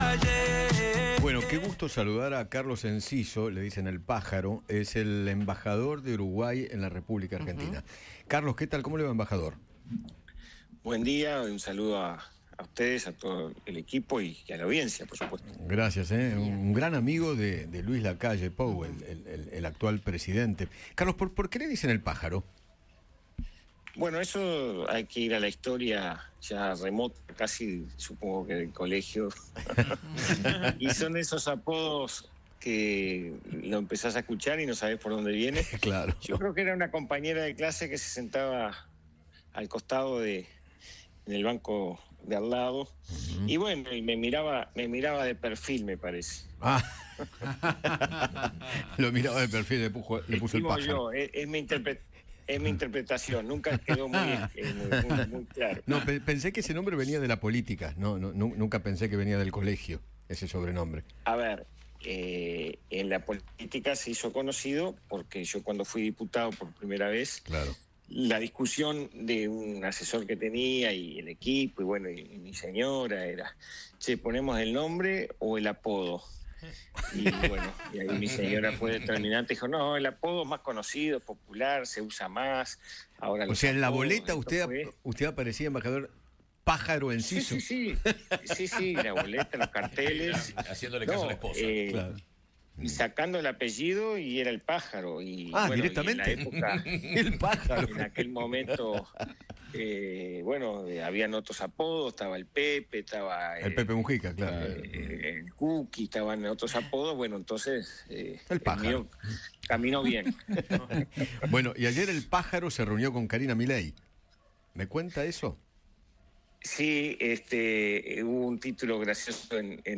Carlos Enciso, embajador de Uruguay en Argentina, conversó con Eduardo Feinmann sobre la reunión que mantuvo con Karina Milei y se refirió a la relación diplomática entre los dos países.